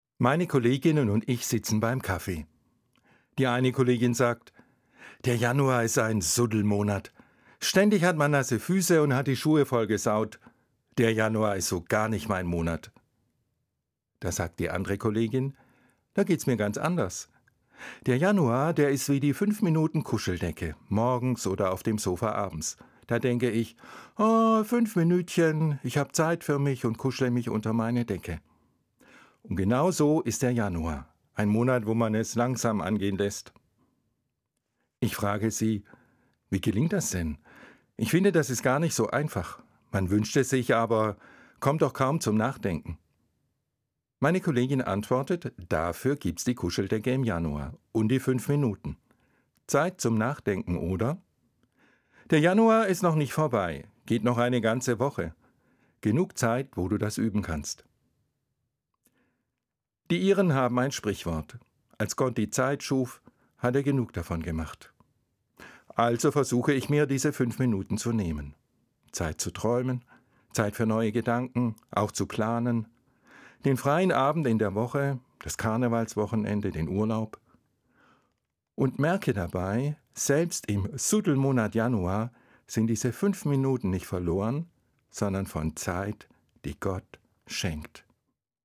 Evangelischer Pfarrer, Limburg